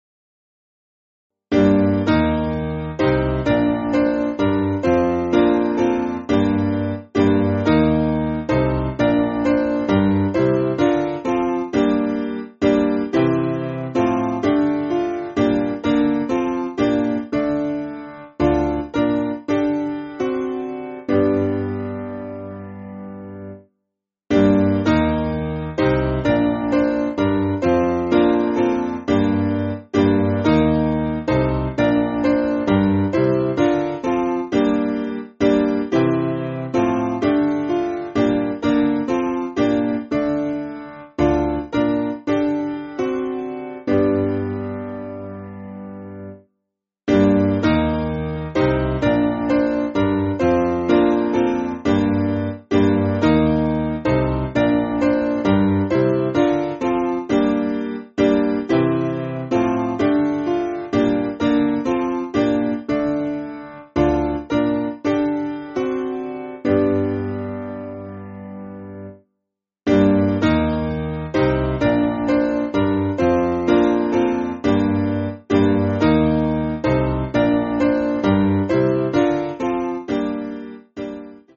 Simple Piano
(CM) 4/Gm